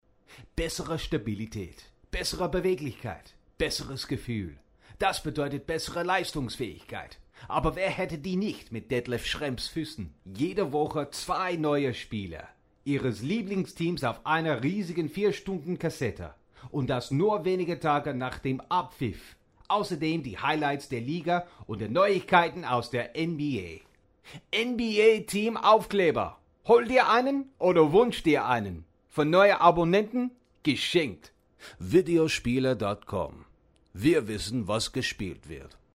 Region/Dialect German, Swiss German, Dutch
Gender Male
Age 30 - 40
Voice Sample